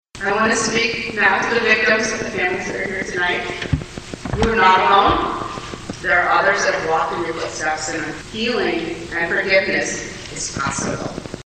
Domestic And Sexual Abuse Services held their Candlelight Vigil in the Auxiliary Gym at Sturgis High School.